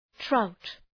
Προφορά
{traʋt}